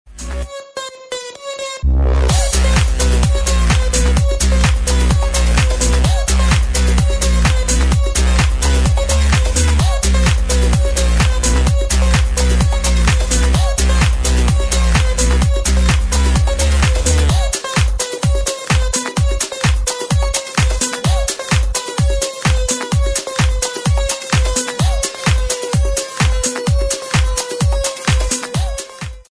• Качество: 128, Stereo
громкие
Драйвовые
dance
Electronic
электронная музыка
без слов
club
энергичные
electro house
Энергичный и драйвовый клубнячок...